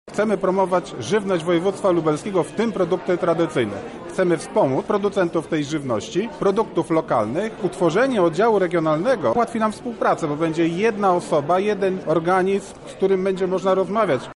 Jest to część większego projektu – mówi Artur Walasek, wicemarszałek Województwa Lubelskiego